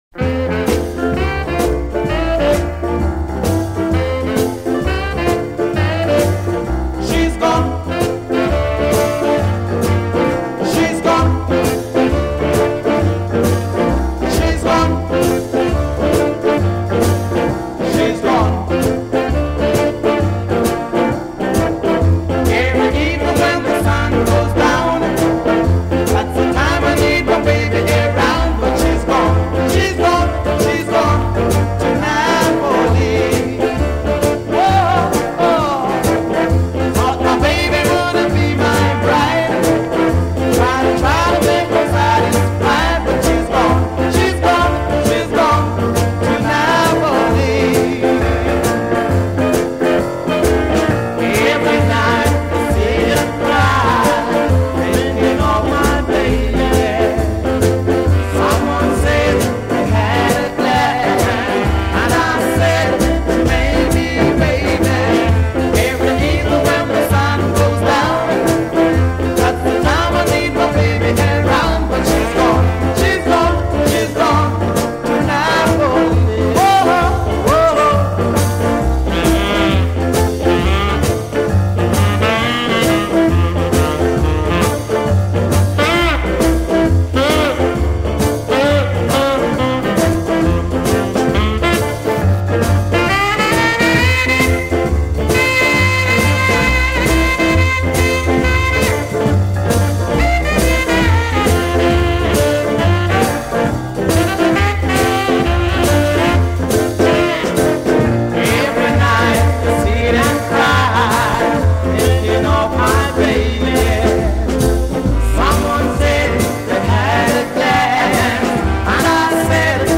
Dubbed The Godfather of Ska